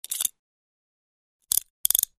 На этой странице собраны различные звуки наручников: от звонкого удара металла до характерного щелчка замка.
Звук защелкивающихся наручников